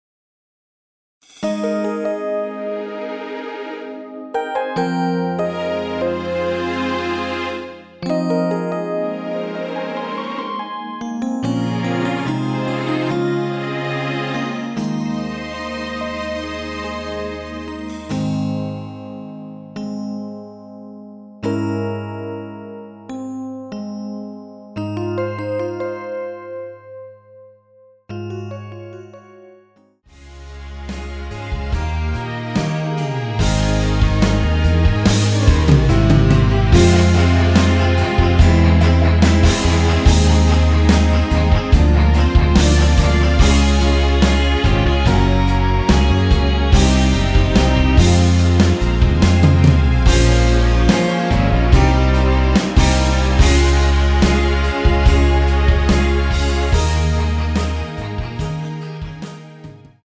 Ab
앞부분30초, 뒷부분30초씩 편집해서 올려 드리고 있습니다.
중간에 음이 끈어지고 다시 나오는 이유는